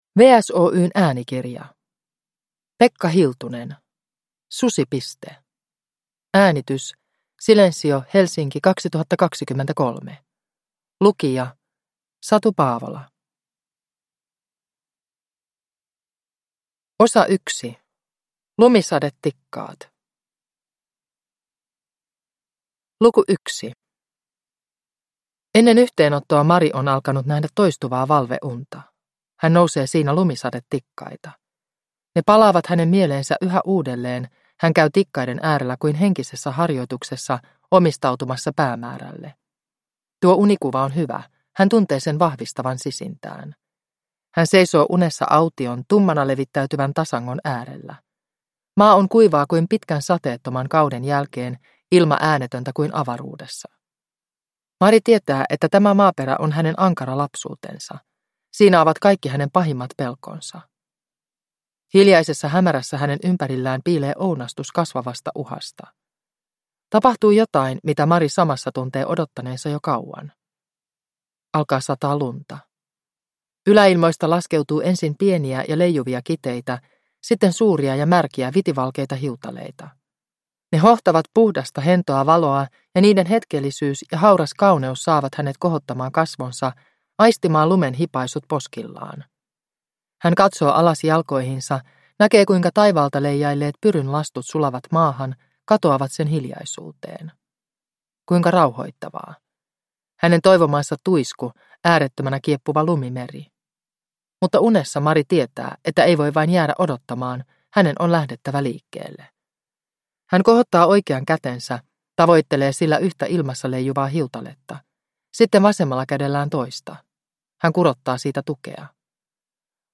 Susipiste – Ljudbok – Laddas ner